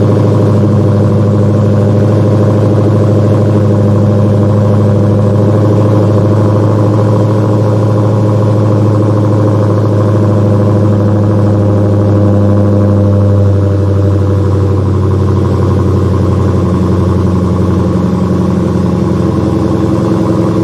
exhaust-mp3.7552